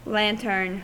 Ääntäminen
Vaihtoehtoiset kirjoitusmuodot (vanhahtava) lanthorn Ääntäminen US UK : IPA : /ˈlæn.tən/ US : IPA : /ˈlæn.tɚn/ Haettu sana löytyi näillä lähdekielillä: englanti Käännös Substantiivit 1.